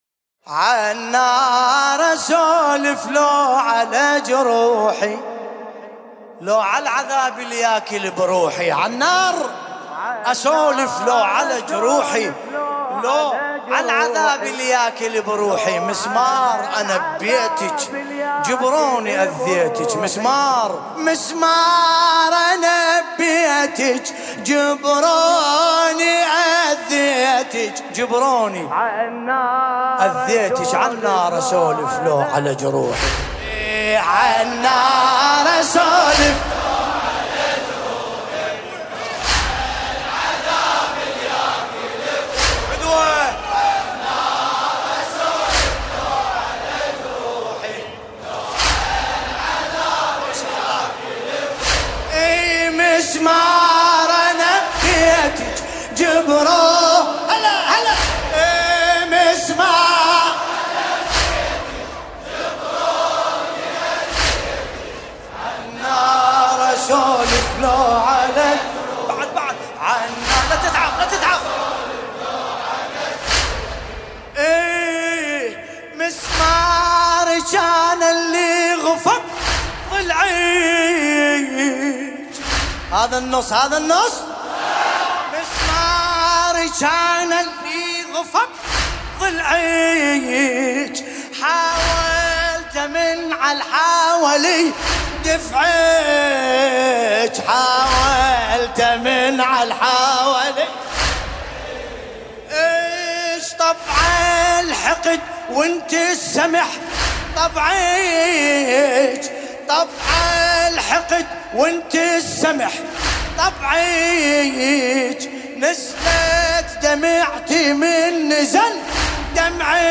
ملف صوتی عالنار أسولف بصوت باسم الكربلائي